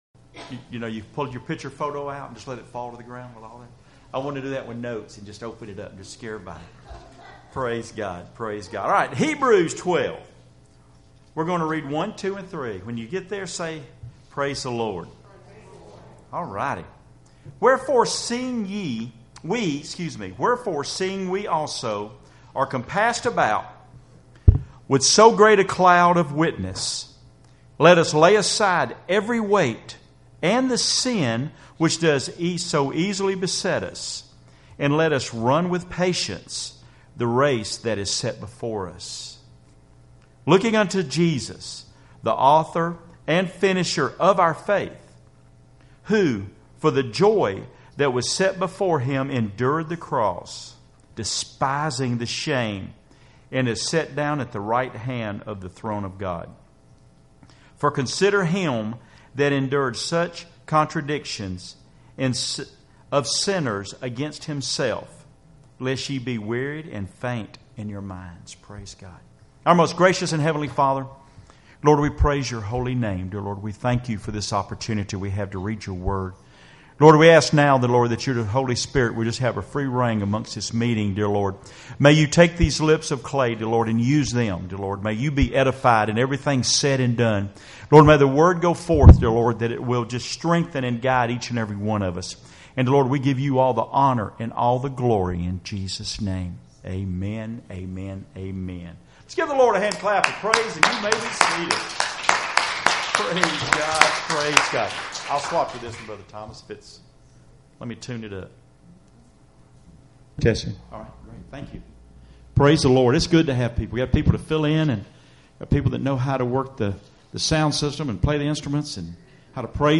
Passage: Hebrews 12:1-3 Service Type: Wednesday Evening Services Topics